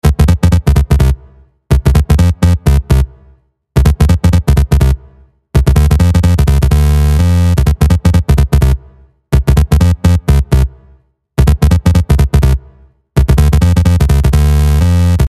в миксе немного теряется вокал. увеличение громкости влияет только хуже - начинается "отделяться". как поступить?